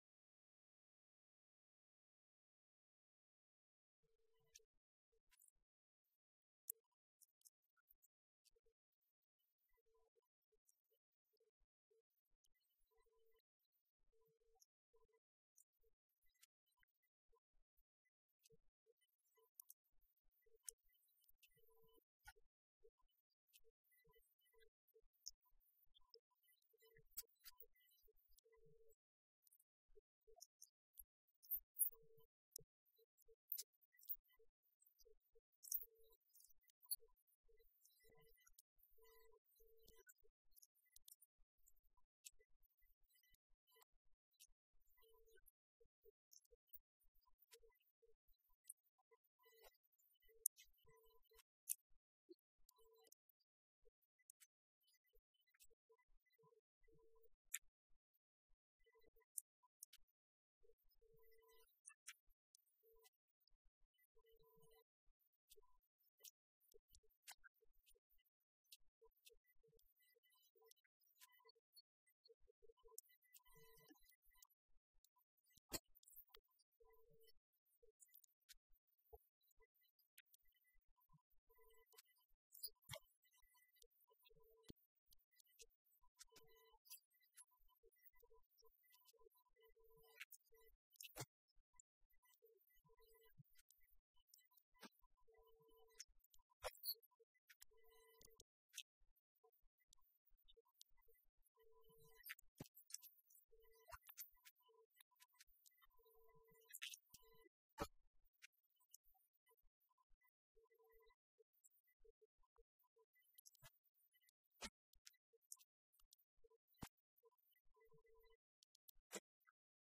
This podcast episode the fourth panel discussion of the day at Artemis London 2025, a session focused on the modernisation and liquidity needs of the ILS market as it grows, from our fourth cat bond and insurance-linked securities (ILS) conference in the City of London, UK, held on September 2nd 2025.